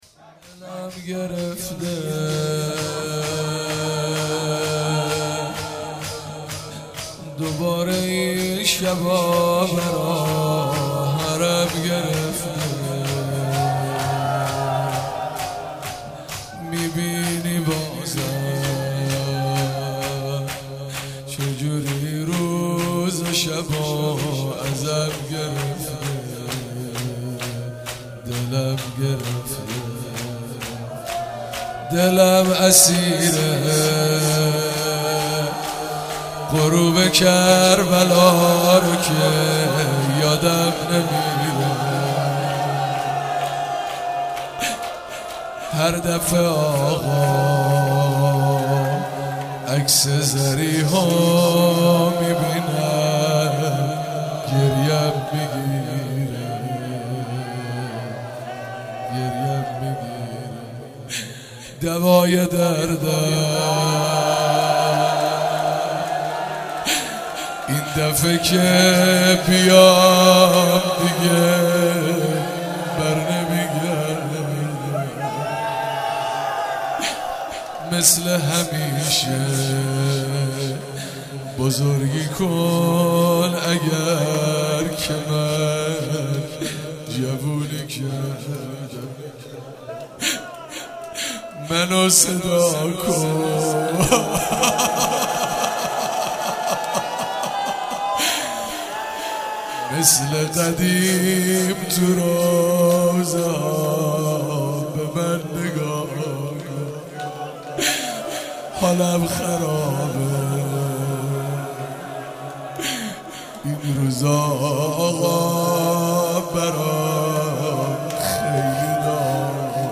اجرا شده در هیئت علمدار مشهد
شور